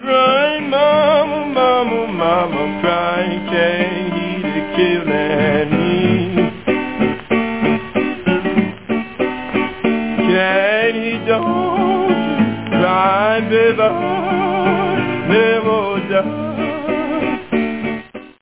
блюза Дельты